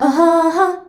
AHAAA   D.wav